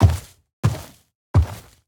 Minecraft Version Minecraft Version snapshot Latest Release | Latest Snapshot snapshot / assets / minecraft / sounds / mob / sniffer / step1.ogg Compare With Compare With Latest Release | Latest Snapshot
step1.ogg